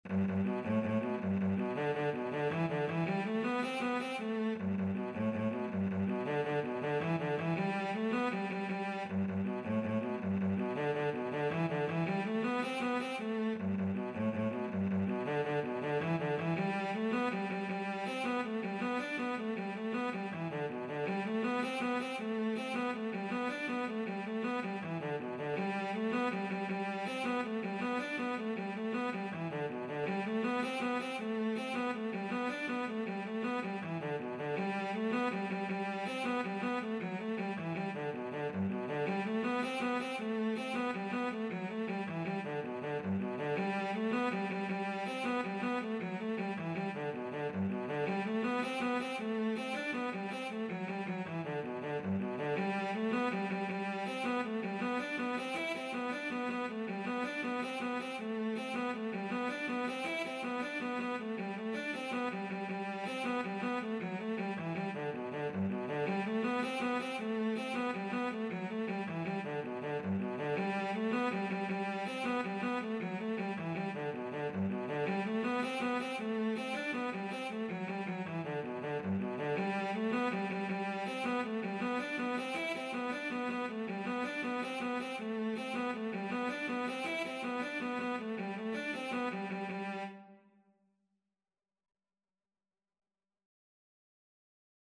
Free Sheet music for Cello
6/8 (View more 6/8 Music)
G major (Sounding Pitch) (View more G major Music for Cello )
Cello  (View more Intermediate Cello Music)
Traditional (View more Traditional Cello Music)